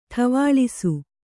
♪ ṭha